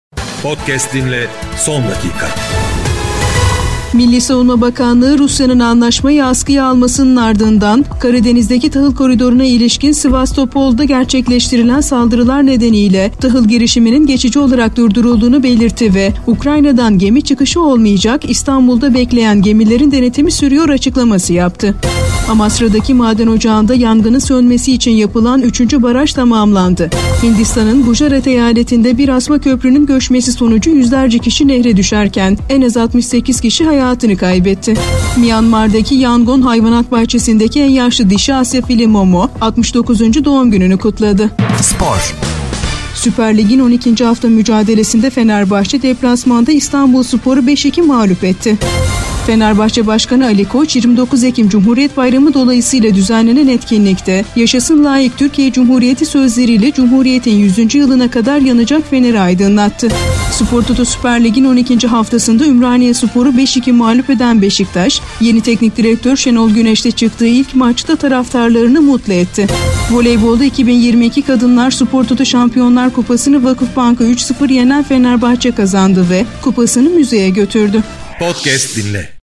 31-EKIM_SABAH-HABER.mp3